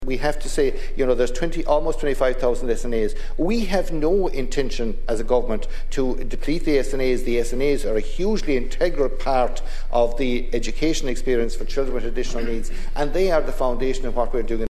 Under questioning in the Dáil, Minister Michael Moynihan suggested those plans will not be revisited: